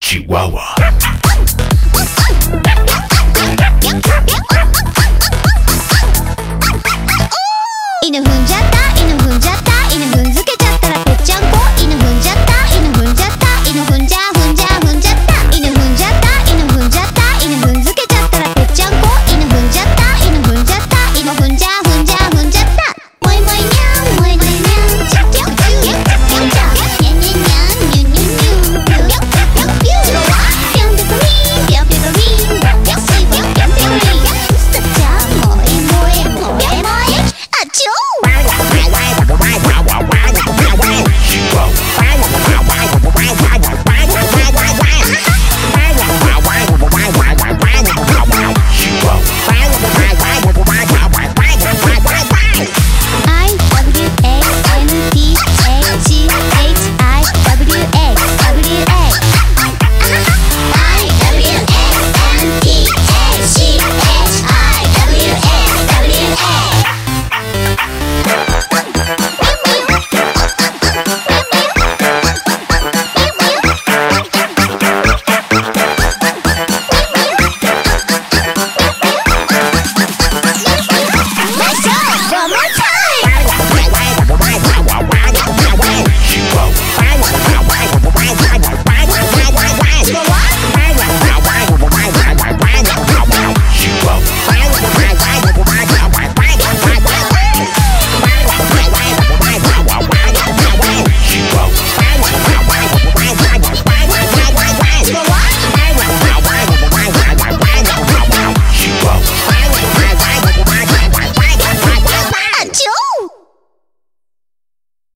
BPM128